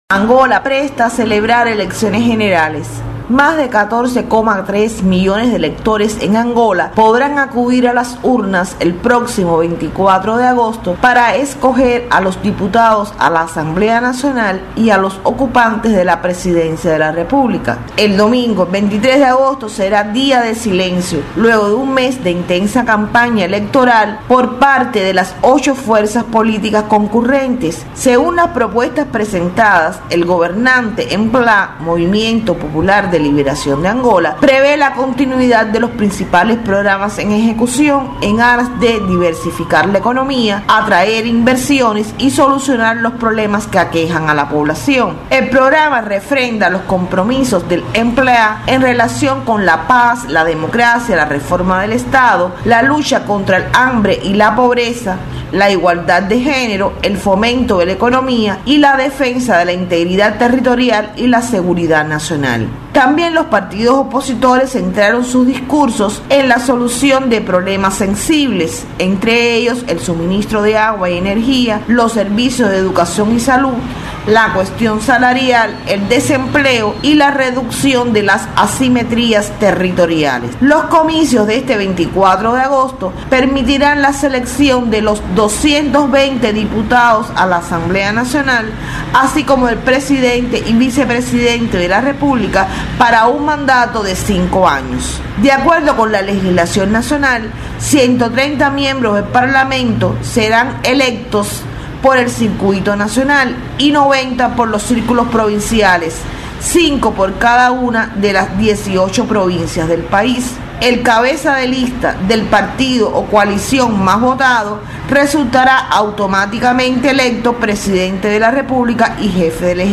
desde Luanda